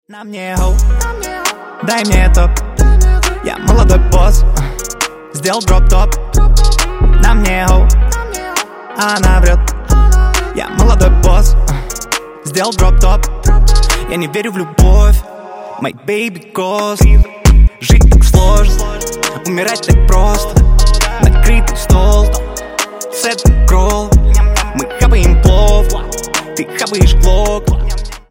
Громкие Рингтоны С Басами
Рэп Хип-Хоп